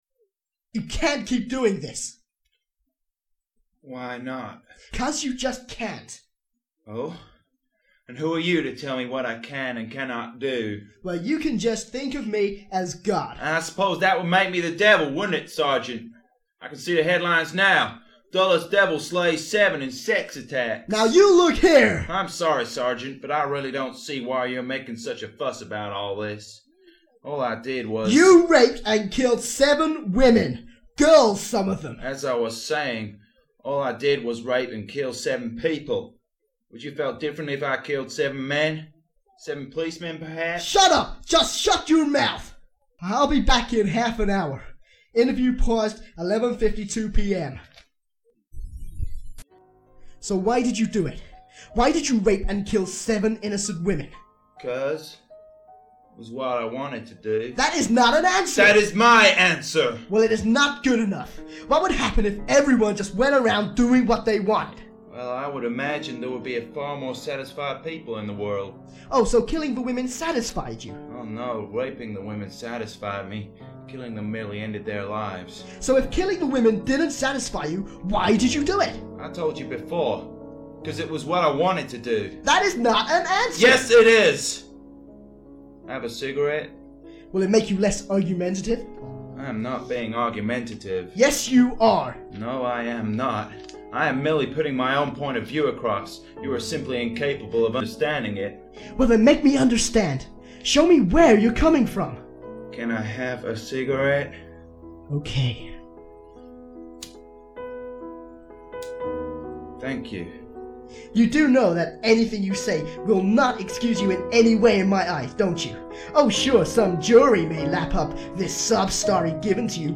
Involvment: Digital Remastering
WARNING This clip contains strong language and deals with issues of violence of a graphic nature.